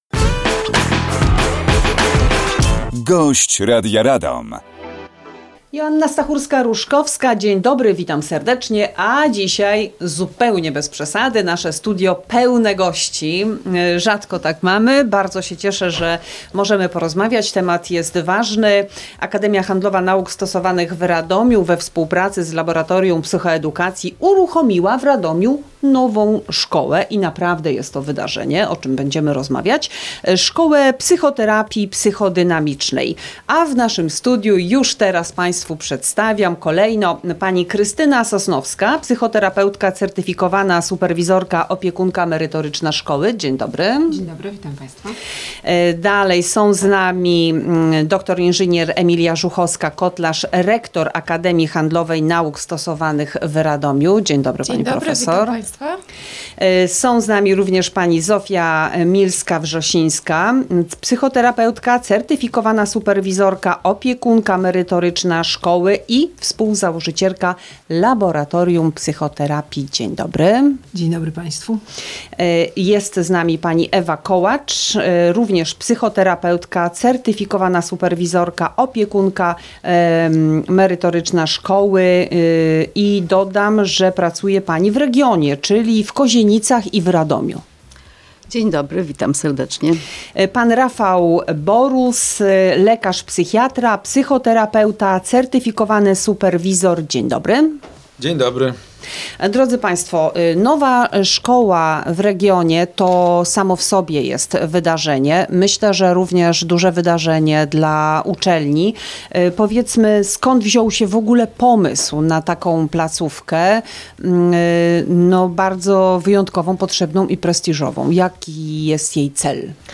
Rozmowa również jest dostępna na facebookowym profilu Radia Radom: